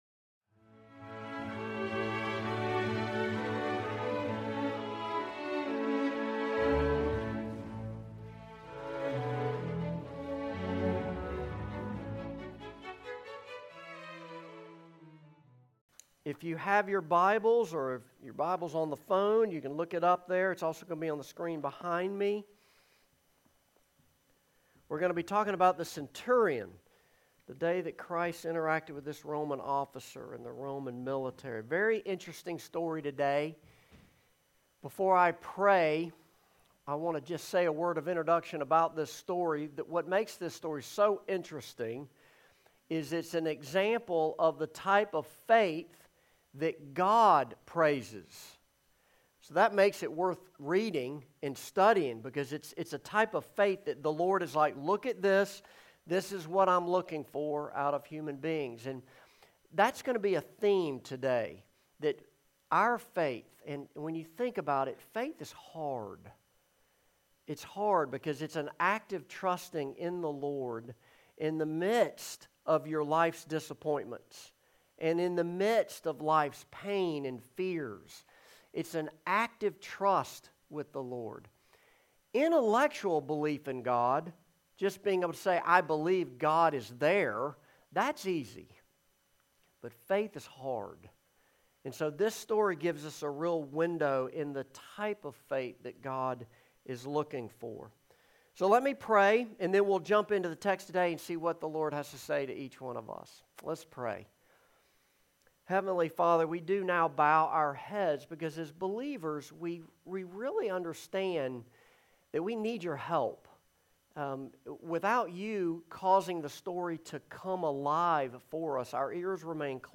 Service Type: Morning Service
Sermon-Intro_Joined-2.mp3